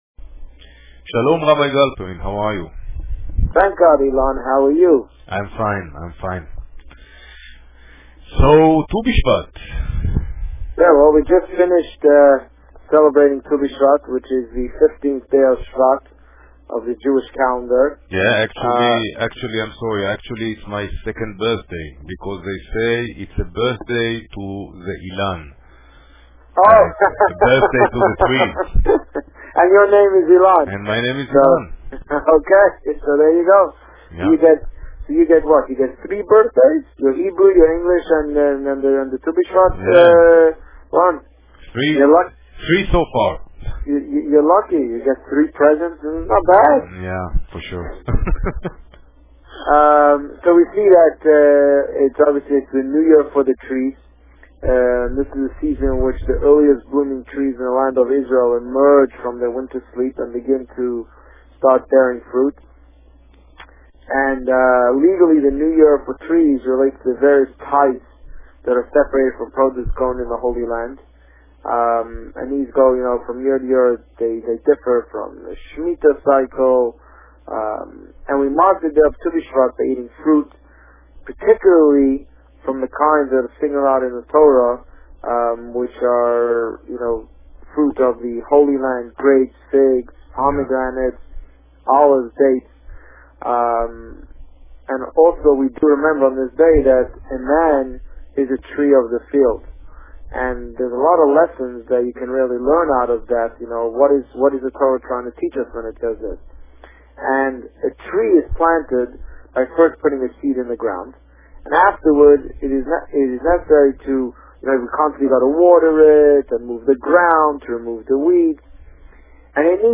The Rabbi on Radio
Parsha Yitro Published: 09 February 2012 | Written by Administrator This week, the Rabbi spoke about a recap of Tu B'Shvat, Parsha Yitro, and plans for the upcoming Purim party. Listen to the interview here .